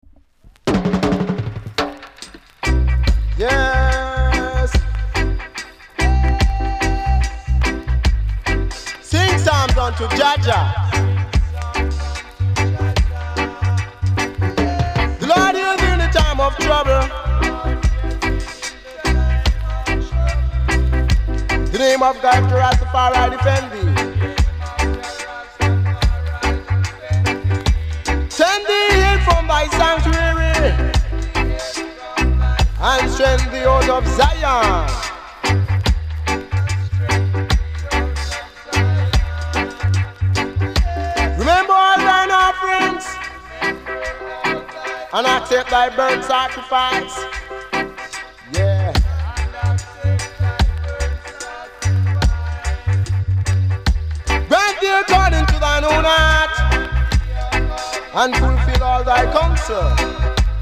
※小さなチリノイズが少しあります。
コメント NICE DEEJAY!!!※DUBサイドにプチパチあります。